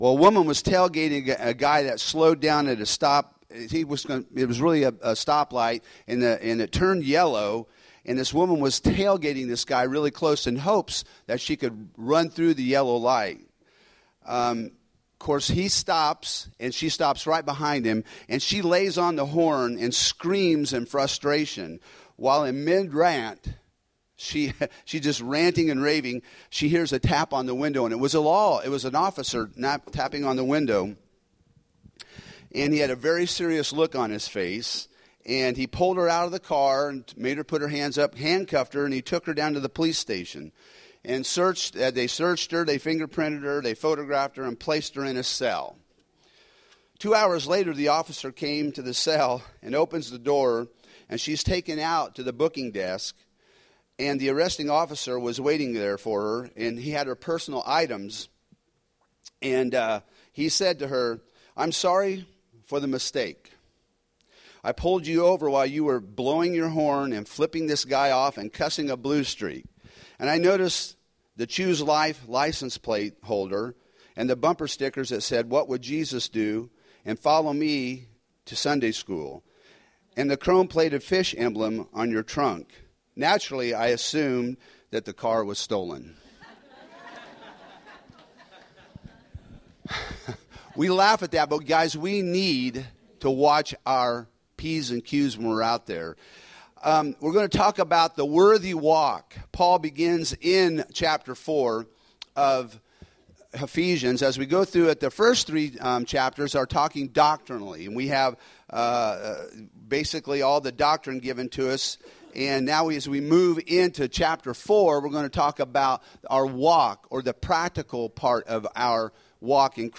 Home › Sermons › Ephesians 4:1~6